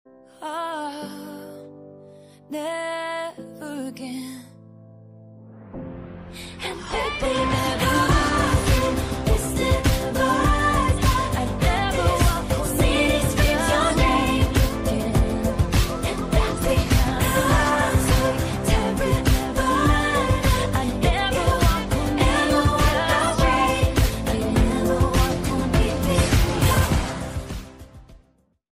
overlapped edit audio